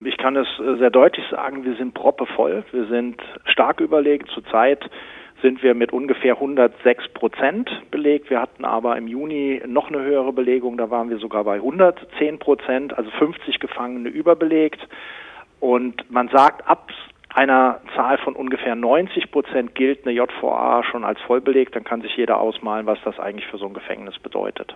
Interview: JVA Wittlich ist stark überbelegt